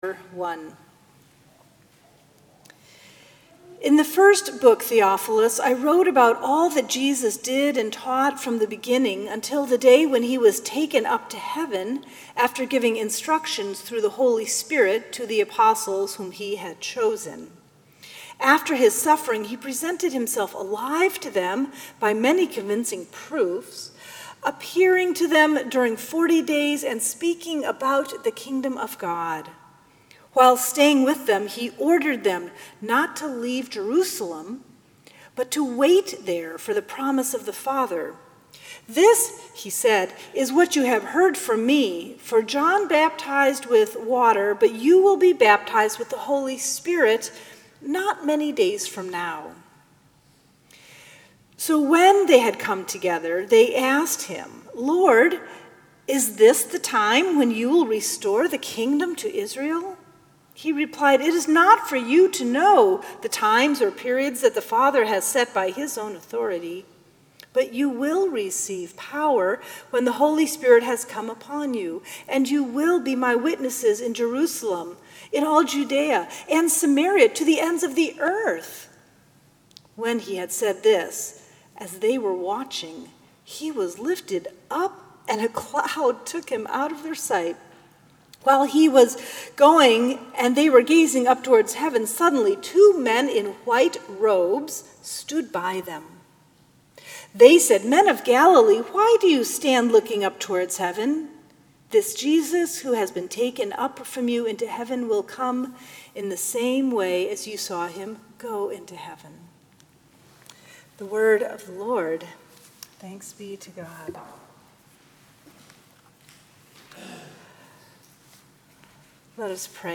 May 28, 2017 Seventh Sunday of Easter Celebration of the Ascension